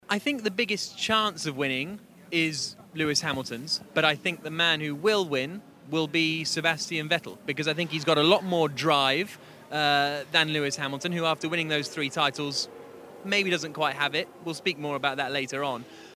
【英音模仿秀】F1新赛季车手前瞻 听力文件下载—在线英语听力室